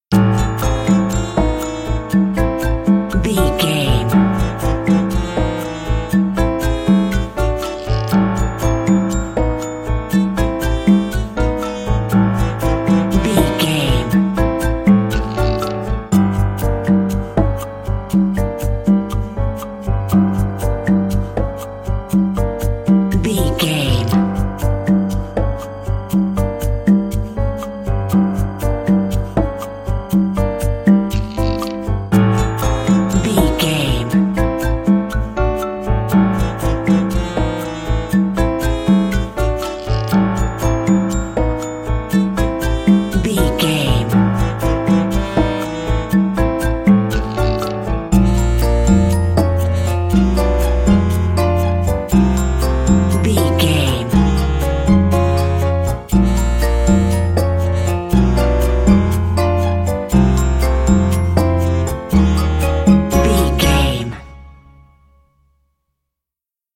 Aeolian/Minor
piano
percussion
flute
silly
circus
goofy
comical
cheerful
perky
Light hearted
quirky